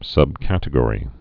(sŭb-kătĭ-gôrē, sŭbkăt-)